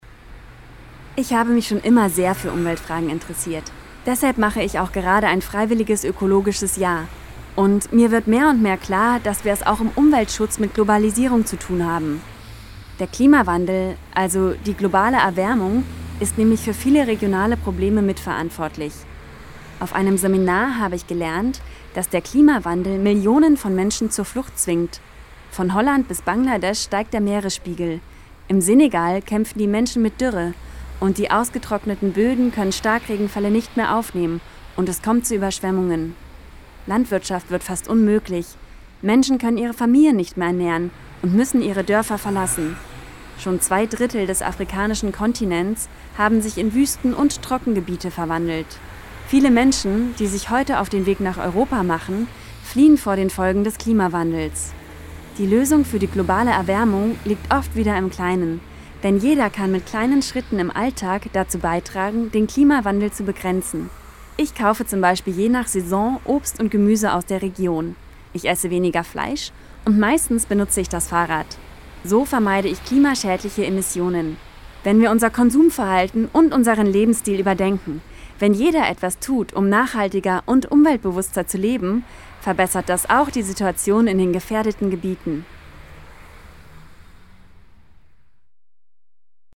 Teenager erzählen:
KF_Audio_Dt-Teenager.mp3